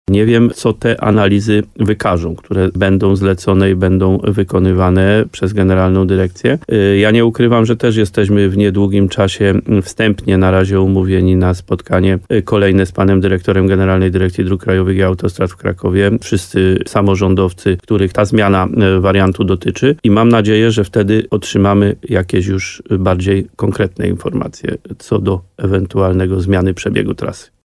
Wójt Adam Wolak mówił w programie Słowo za Słowo w radiu RDN Nowy Sącz, że po rozmowach w Ministerstwie Infrastruktury, które odbyły się na początku roku, jest przychylność urzędników, aby jeszcze raz przeanalizować temat.